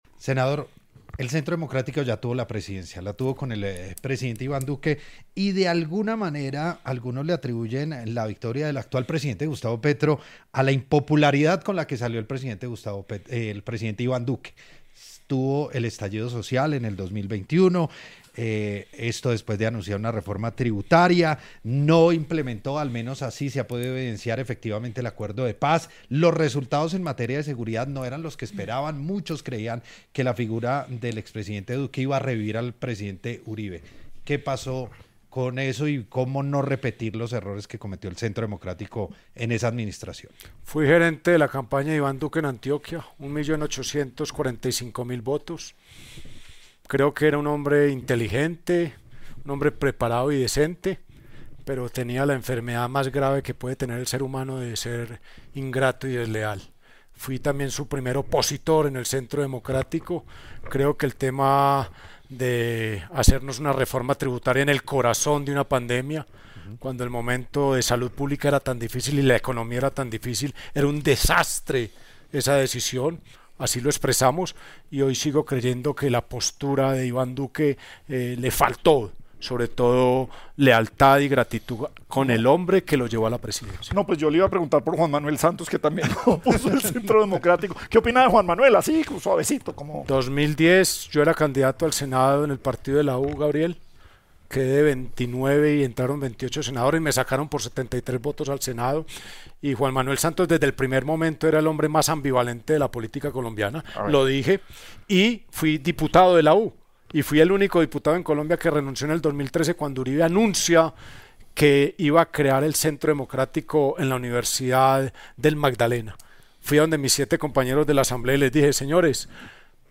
Las declaraciones se dieron durante su participación en el programa ‘Sin Anestesia’ de La Luciérnaga, en Caracol Radio.